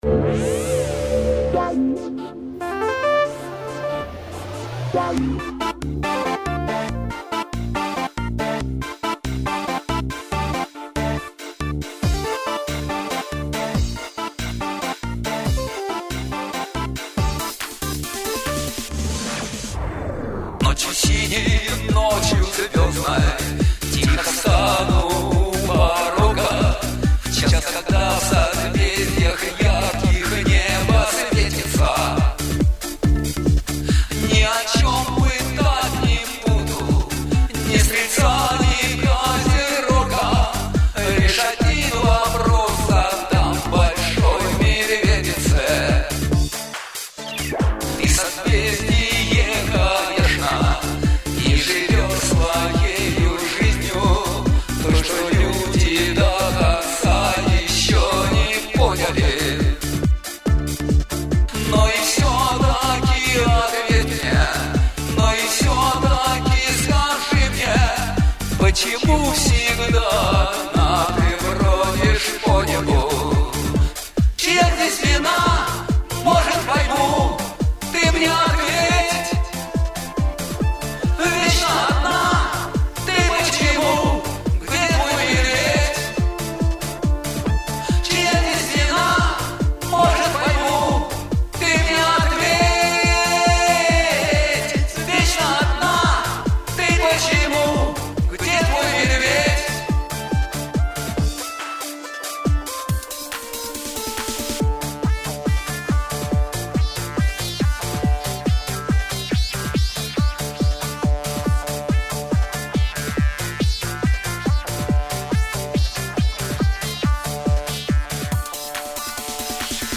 Две хорошие песни превращены в танцульки